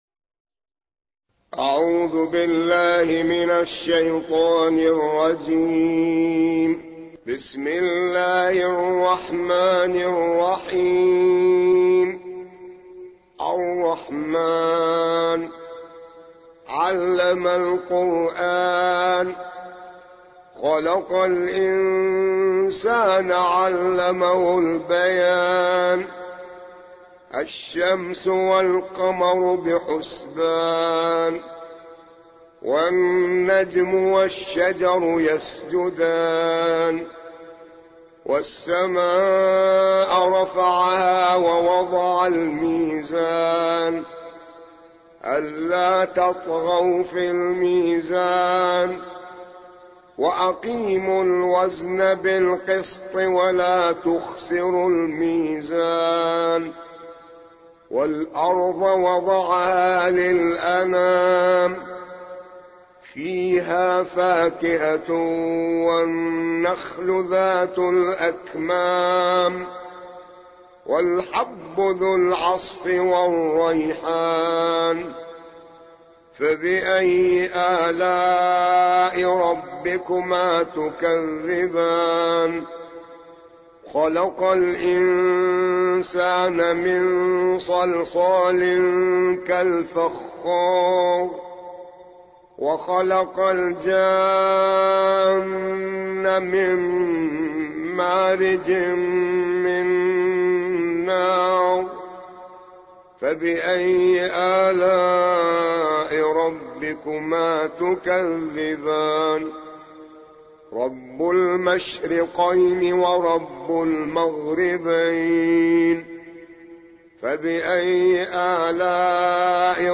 55. سورة الرحمن / القارئ